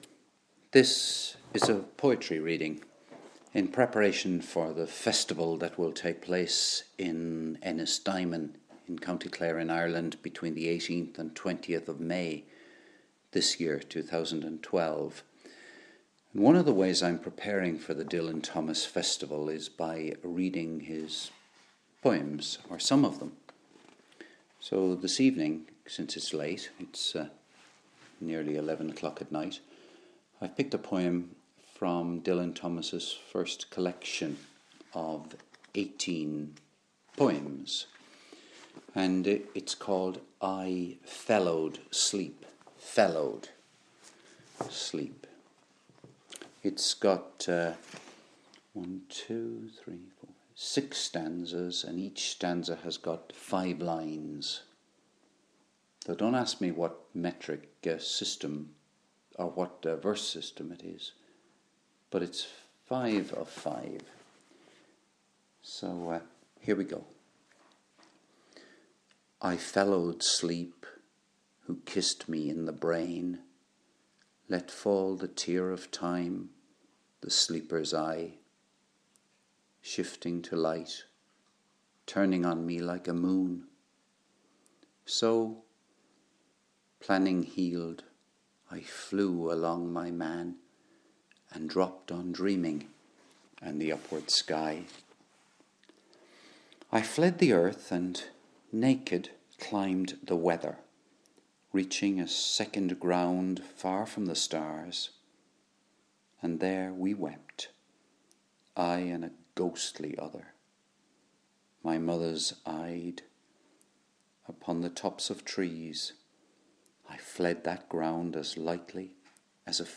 Dylan Thomas: "I feathered sleep" - a reading